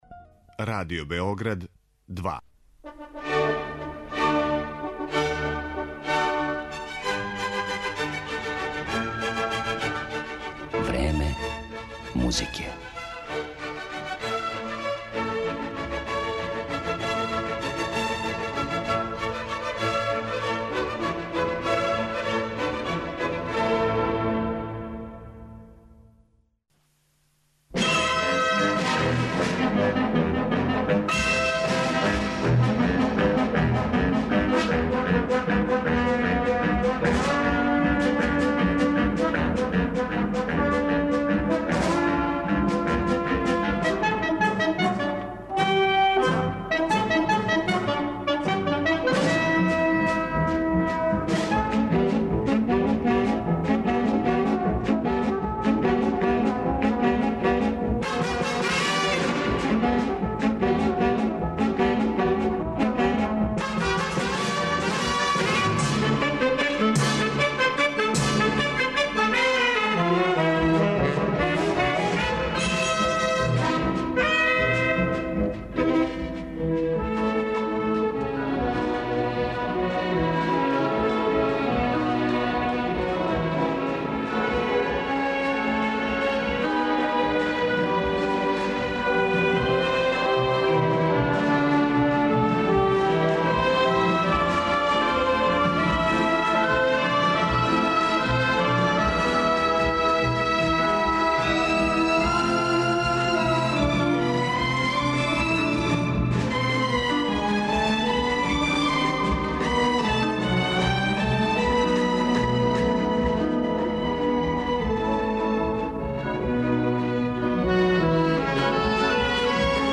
Гуно, Прокофјев, Берлиоз, Чајковски, Бернштајн, неки су од аутора који су својом музиком, а у форми опера, балета, симфонијских дела или мјузикла, описали љубав двоје младих из Вероне.